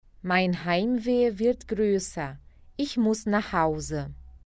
Dicas de pronúncia:
[h] som feito na garganta
[ei] som de ai
[w] som de v